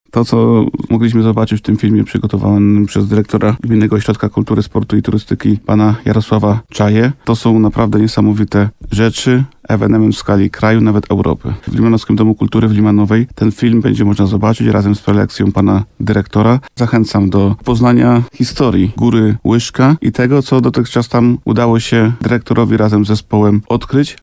Na temat tego miejsca powstał film dokumentalny – mówi wójt gminy Bogdan Łuczkowski.